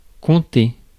Ääntäminen
Synonyymit raconter Ääntäminen France: IPA: [kɔ̃.te] Haettu sana löytyi näillä lähdekielillä: ranska Käännös Ääninäyte Verbit 1. tell US 2. recount 3. relate US 4. narrate Määritelmät Verbit Exposer par un récit .